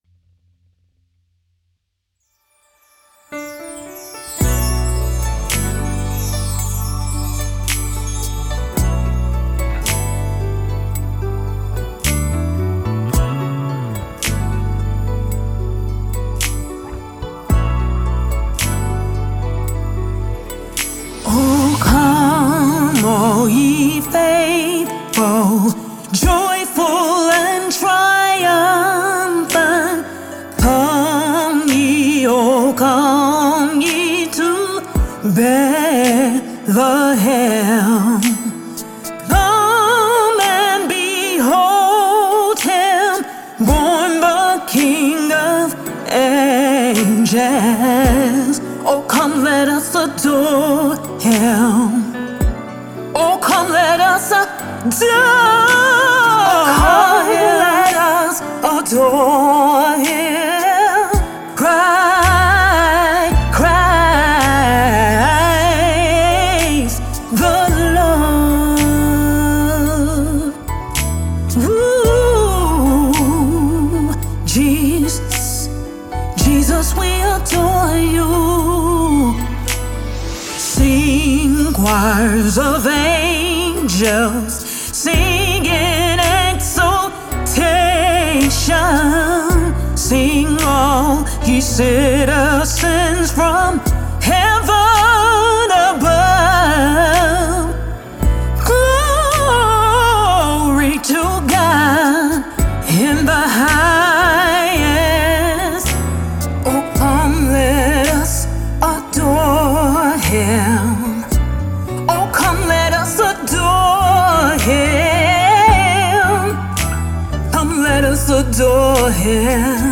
Gospel
a musical celebration of the Savior’s birth.
creating an atmosphere of reverence and celebration.